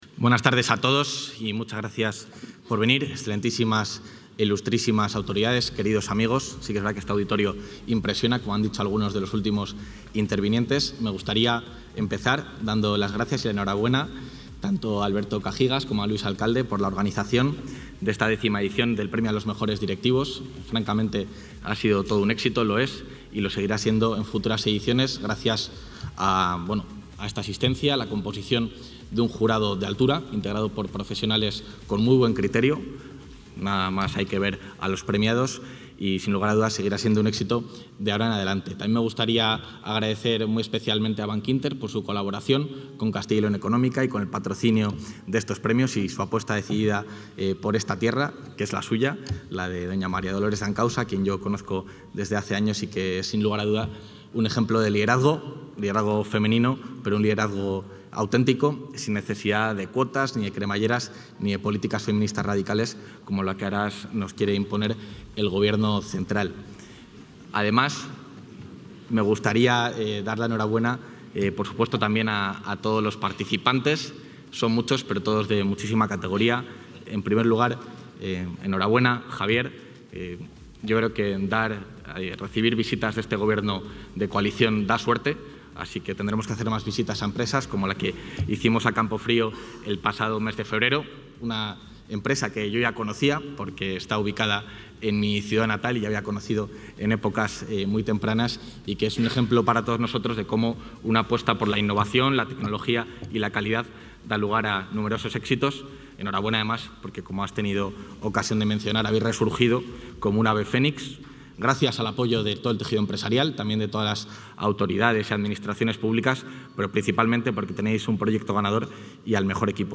Intervención del vicepresidente de la Junta.
El vicepresidente de la Junta de Castilla y León, Juan García-Gallardo, ha clausurado durante la tarde de hoy en el Auditorio I de la Feria de Valladolid la gala de entrega de los X Premios al Mejor Directivo de Castilla y León, organizados por Castilla y León Económica.